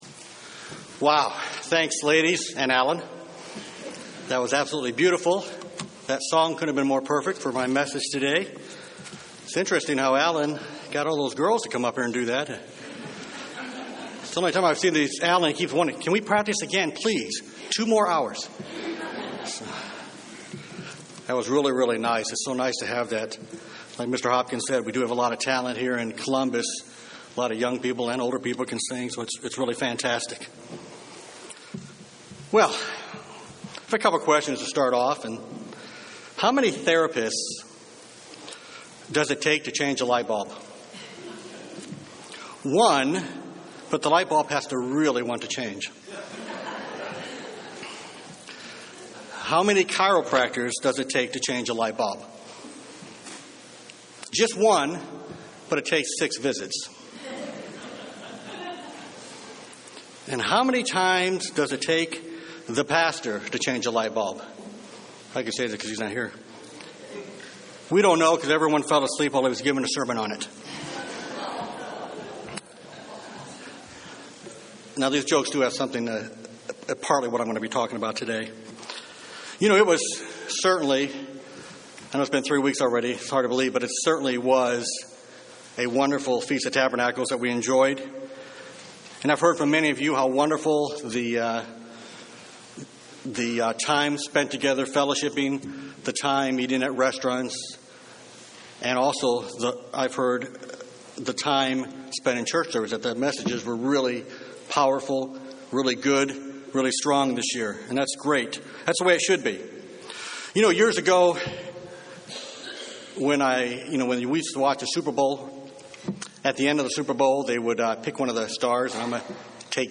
UCG Sermon Studying the bible?
Given in Columbus, OH